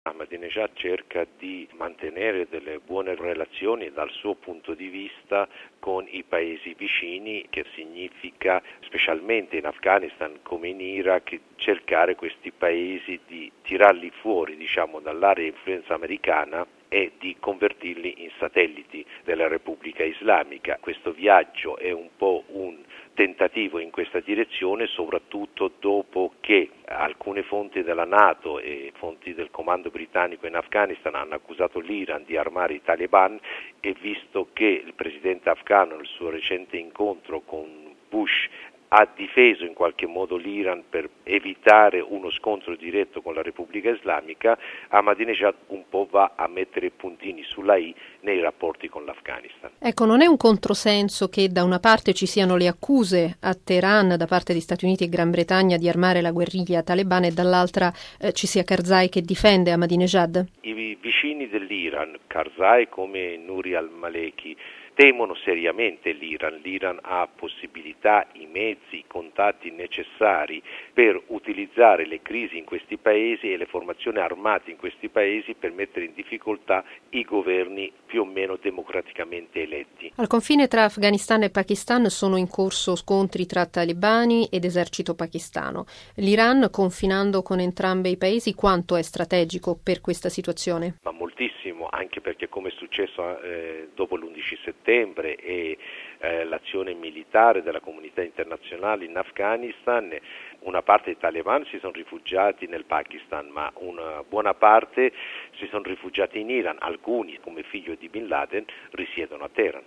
Ma che significato assume allora la missione di Ahmadinejad a Kabul? Risponde il giornalista iraniano